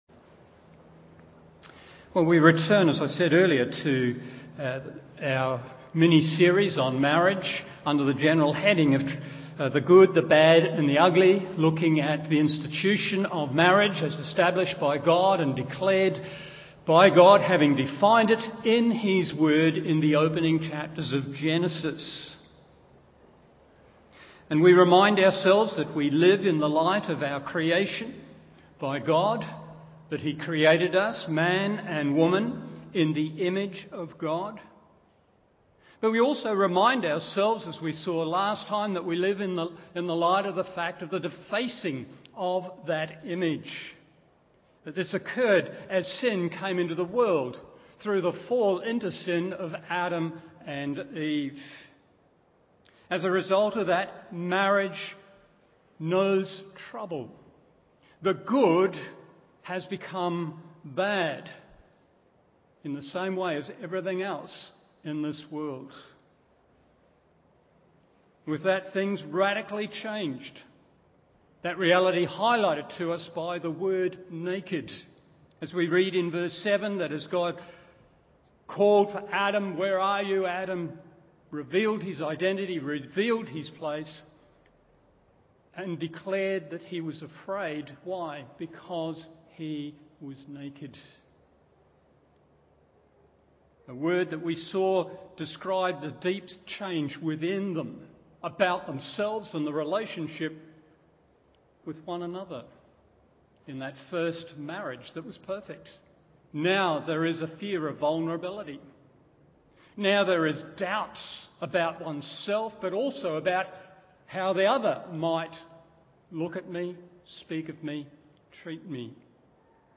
Morning Service Gen 3:16b 1.